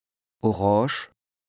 L’alsacien regroupe plusieurs variantes dialectales du Nord au Sud de l’Alsace.
Nous avons tenté d’être représentatifs de cette diversité linguistique en proposant différentes variantes d’alsacien pour chaque lexique, à l’écrit et à l’oral.